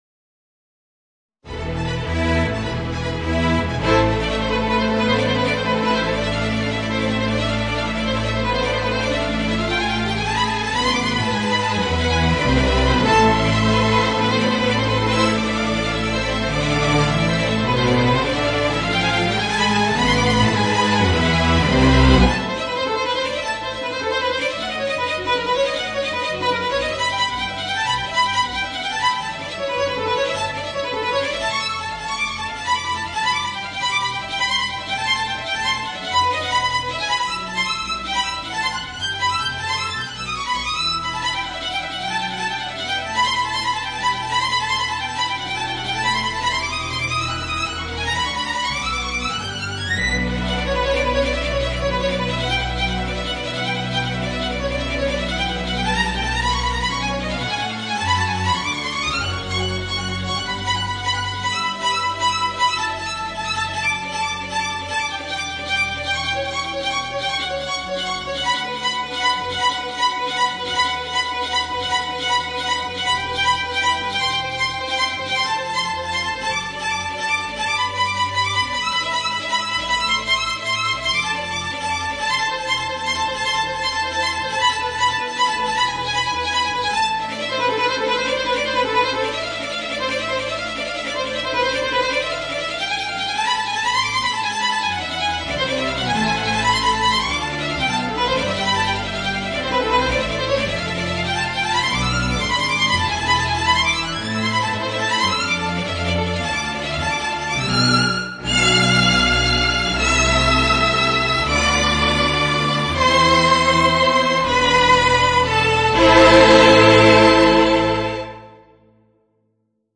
Voicing: Violin and String Orchestra